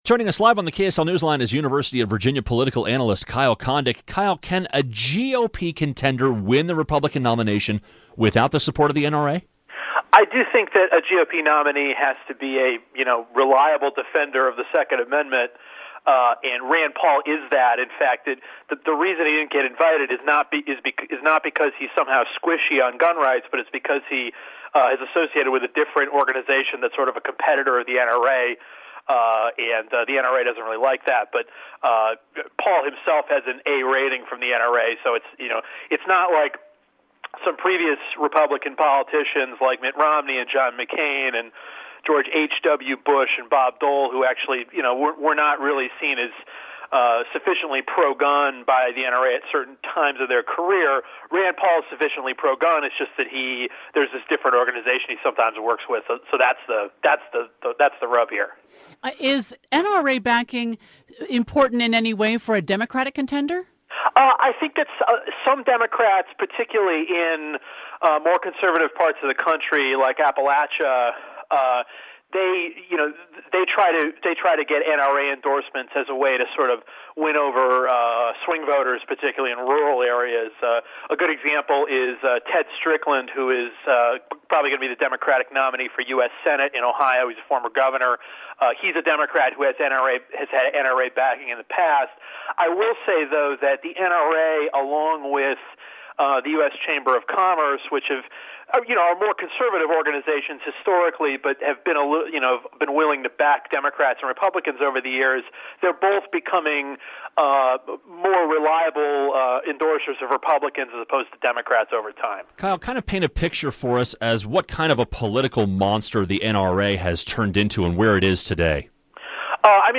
live on Utah's Morning News on KSL.